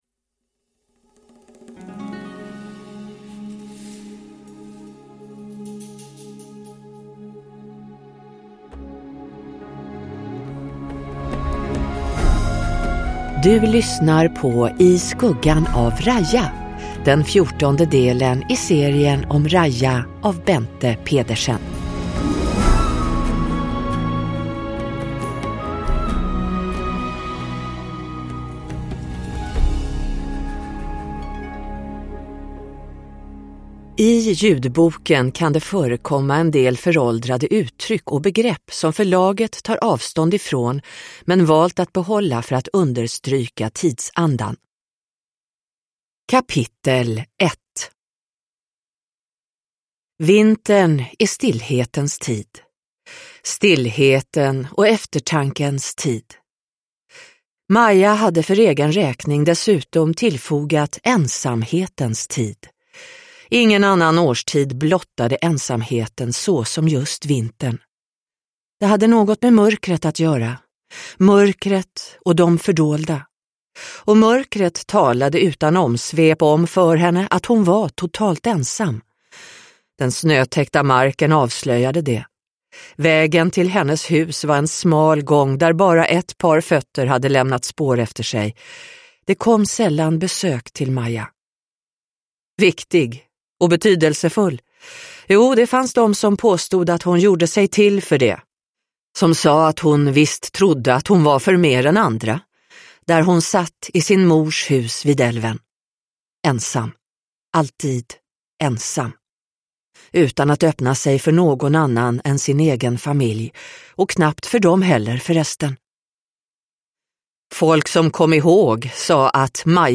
I skuggan av Raija – Ljudbok